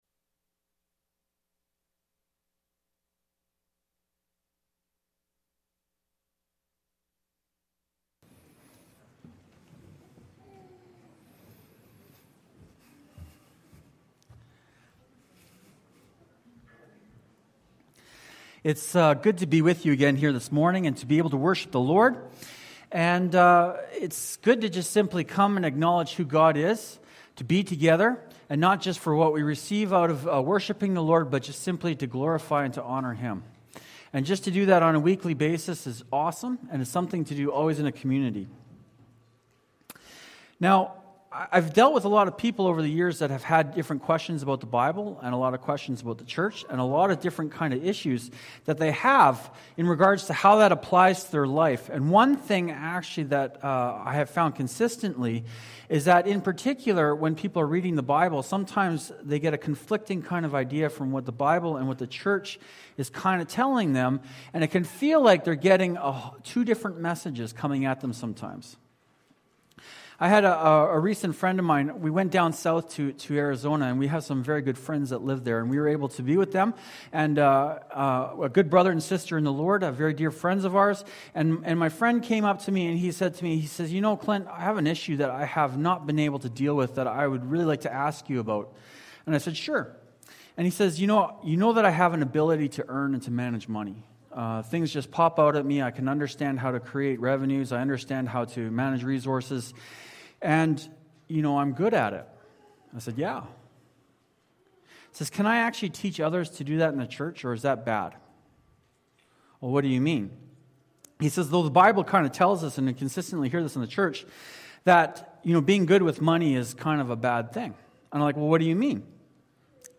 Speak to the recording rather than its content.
Let’s Pick on the Rich – Fairview Cornerstone Baptist Church